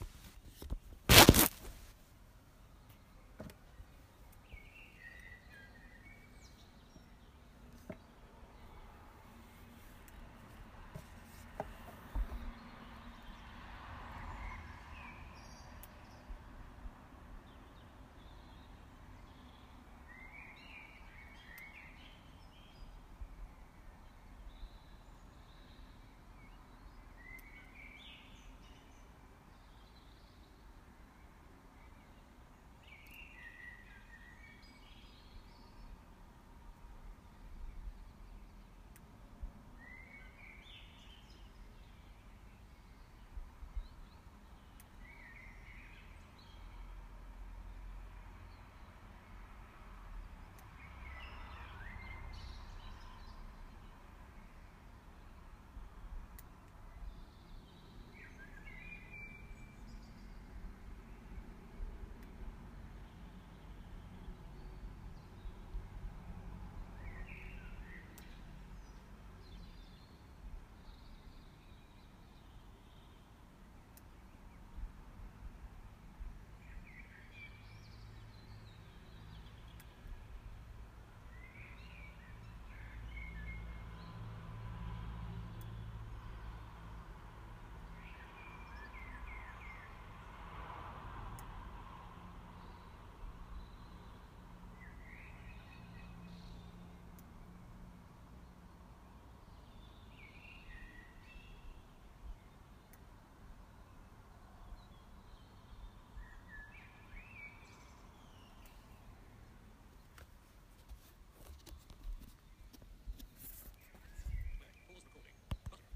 Blackbird at dawn, Tuesday 7 March 2017